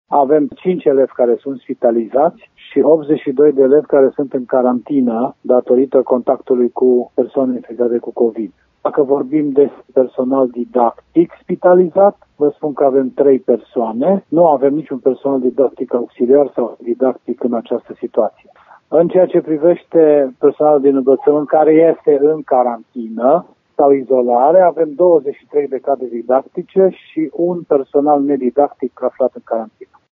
Numărul îmbolnăvirilor se ridică la opt, spune şeful Inspectoratului Şcolar Arad, Marius Gondor.